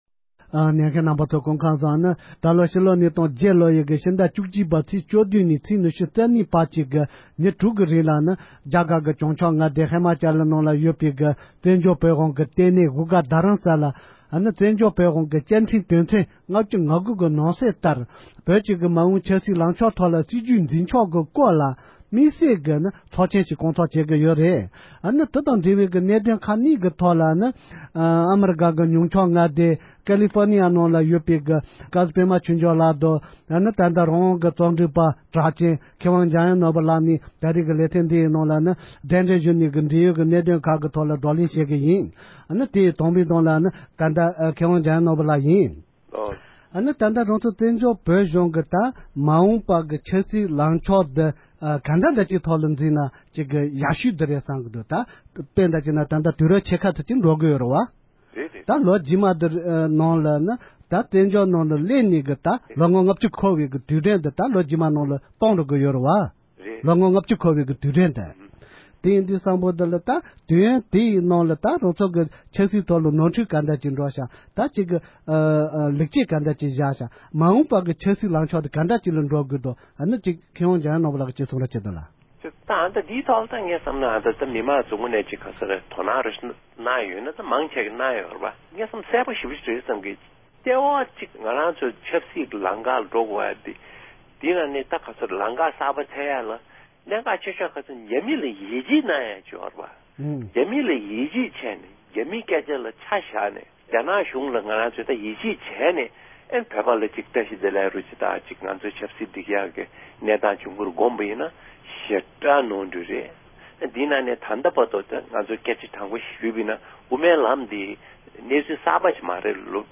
བཀའ་ཟུར་པདྨ་ཆོས་འབྱོར་ལཌ་དང་མཁས་དབང་འཇམ་དབྱངས་ནོར་བུ་ལཌ་རྣམ་གཉིས་ཀྱིས་མ་འོང་བོད་ཀྱི་ཆབ་སྲིད་དང་སྲིད་བྱུས་སྐོར་གསུངས་བ།